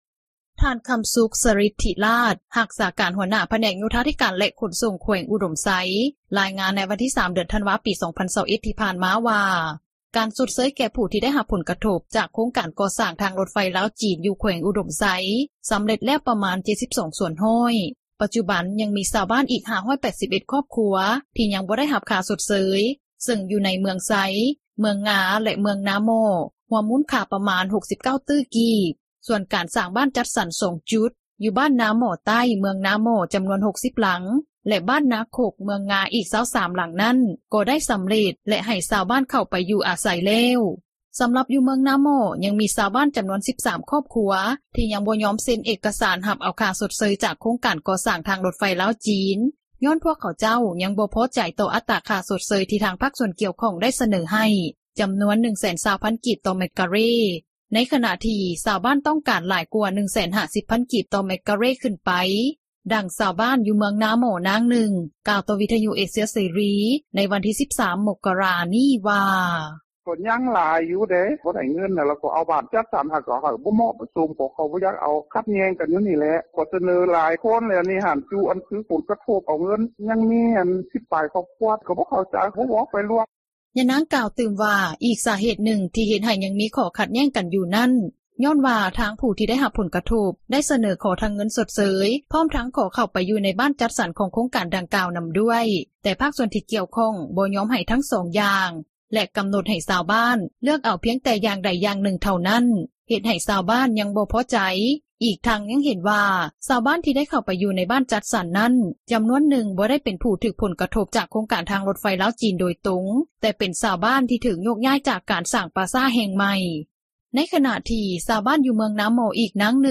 ດັ່ງຊາວບ້ານ ຢູ່ເມືອງນາໝໍ້ ນາງນຶ່ງ ກ່າວຕໍ່ວິທຍຸເອເຊັຽເສຣີ ໃນວັນທີ 13 ມົກກະຣາ ນີ້ວ່າ: